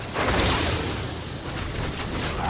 Download Magic Power Meme sound effect for free.